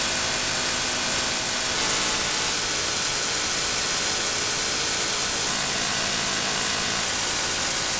Audio sample USBUpper Side Band Modulation (Radio, referring to reception and modulation mode)Universal Serial Bus (Computer, referring to USB Ports and cables) 48k